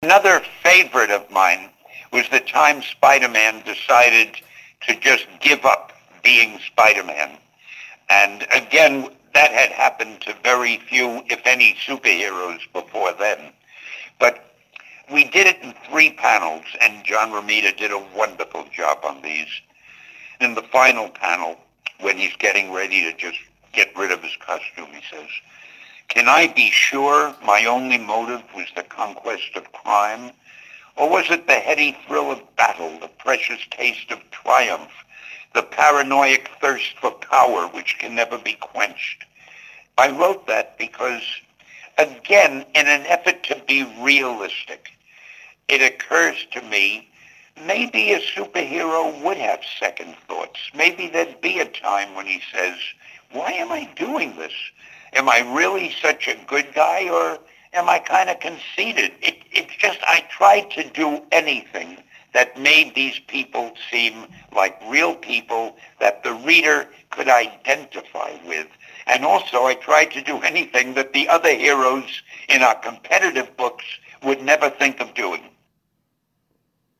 Unsterbliche Worte von Stan Lee (mp3):